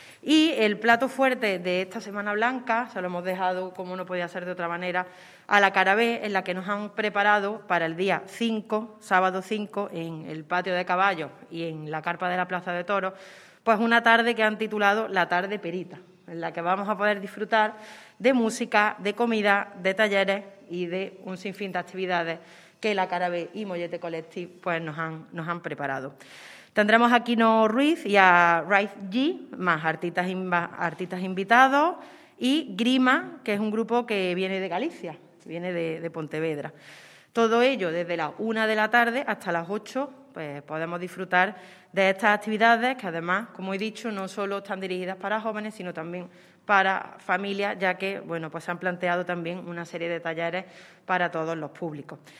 La teniente de alcalde delegada de Juventud, Elena Melero, ha presentado dicha programación en rueda de prensa en la mañana de hoy junto a representantes del colectivo “La Cara B”, promotores de un evento musical al que se dará cabida.
Cortes de voz